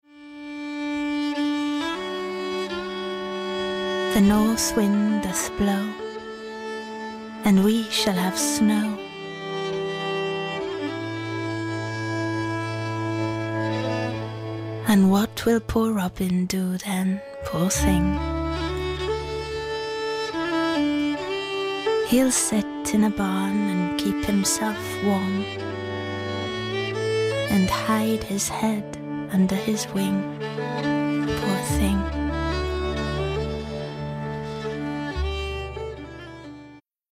40/50's Welsh,
Lyrical/Emotive/Distinctive